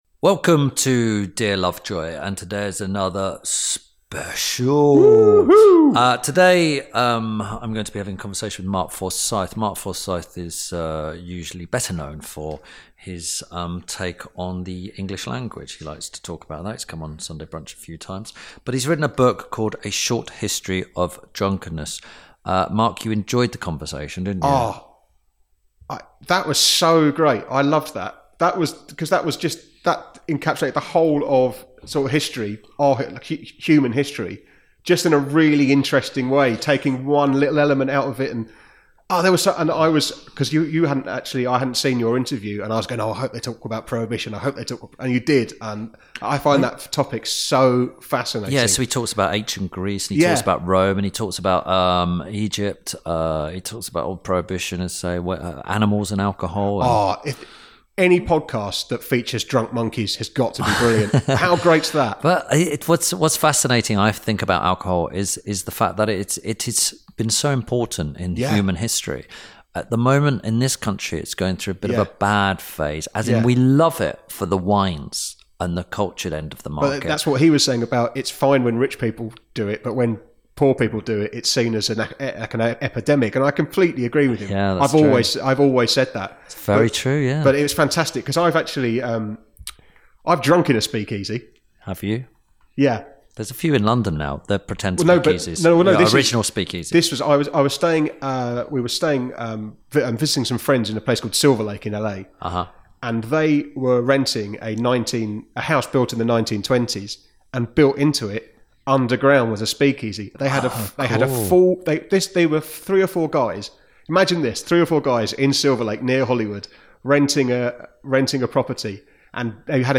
This week Tim Lovejoy talks to author Mark Forsyth about his book 'A Short History of Drunkenness'. They discuss how alcohol has been a part of our lives even before we were humans, drunk monkeys falling out of trees and prohibition.